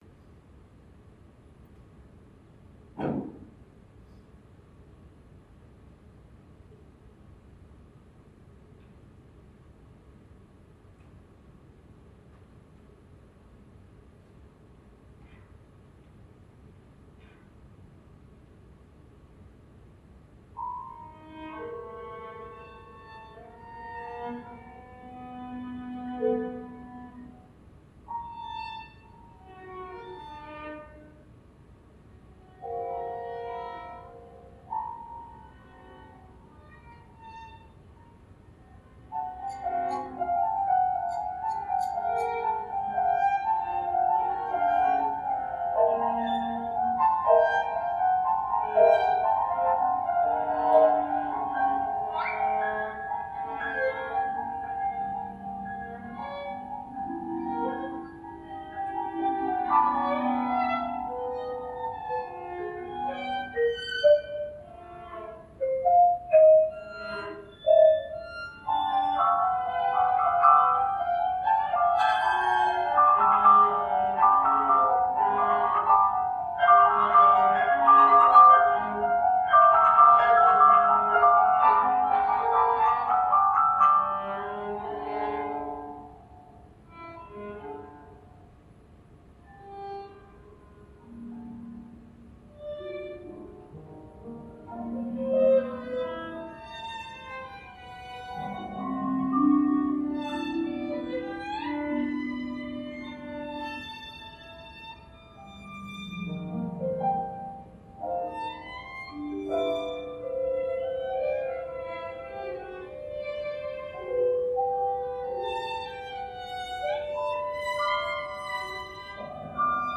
Instrumentation: any number of players